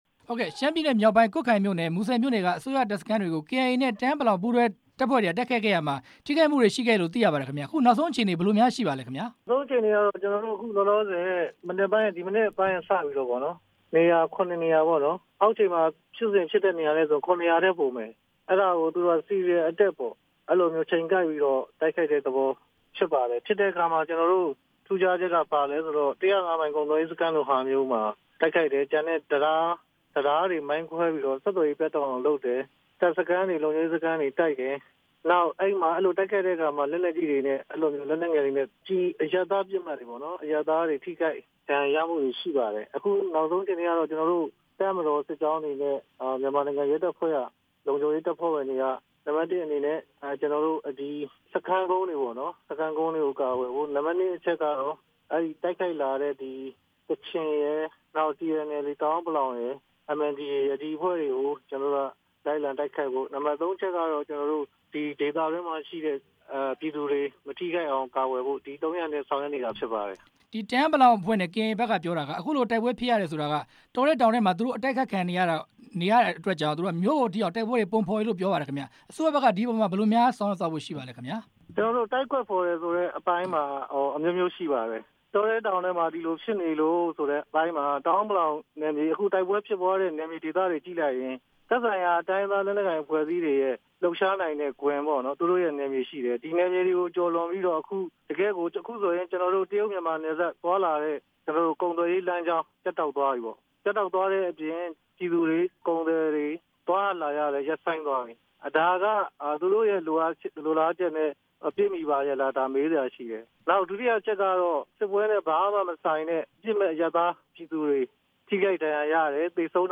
သမ္မတရုံး ဒုတိယ ညွှန်ကြားရေးမှူးချုပ် ဦးဇော်ဌေးနဲ့ မေးမြန်းချက်